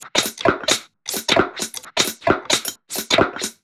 Index of /musicradar/uk-garage-samples/132bpm Lines n Loops/Beats
GA_BeatDSweepz132-06.wav